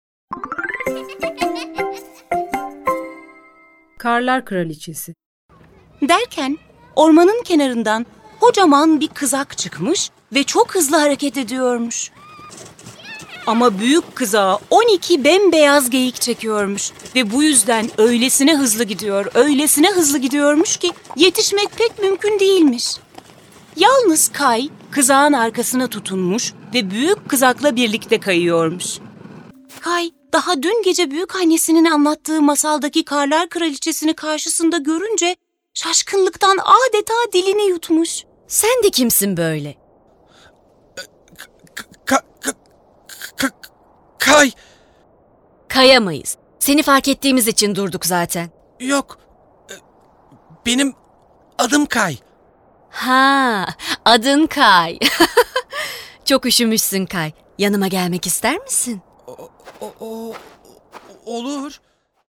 Karlar Kraliçesi Tiyatrosu